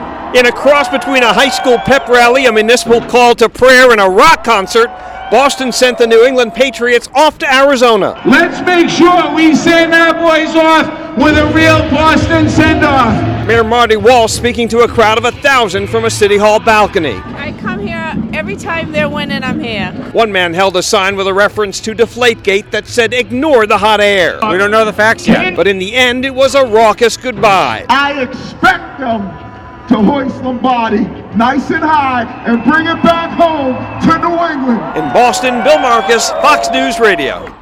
Boston gives Patriots a raucous send off
(BOSTON) JAN 26 – A PEP RALLY FOR THE SUPER BOWL BOUND NEW ENGLAND PATRIOTS IN GOVERNMENT CENTER IN BOSTON TODAY (MONDAY)….